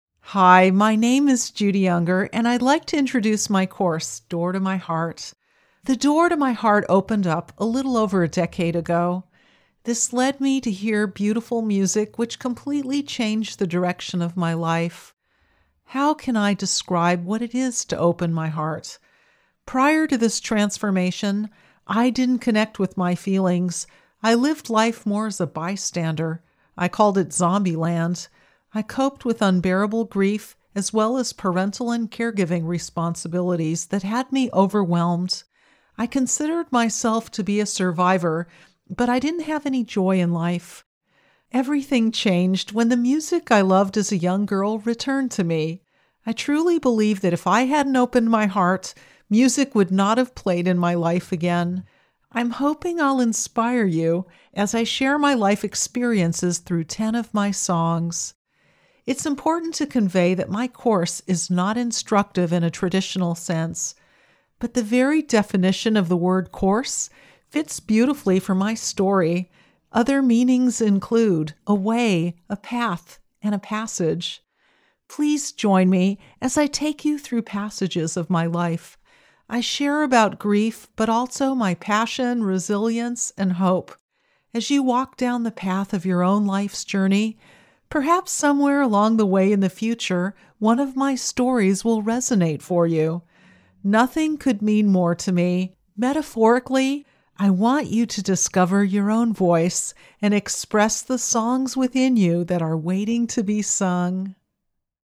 Mixing in background music is very fun and creative for me. I even started recording some slow nylon guitar meanderings to add to my lessons.